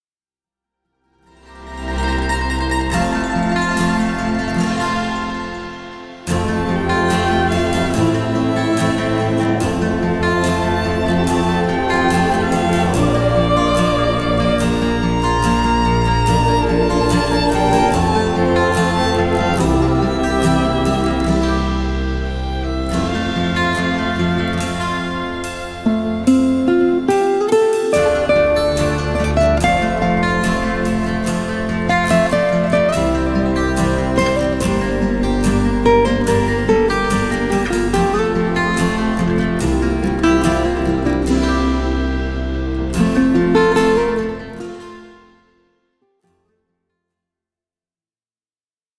Play-Back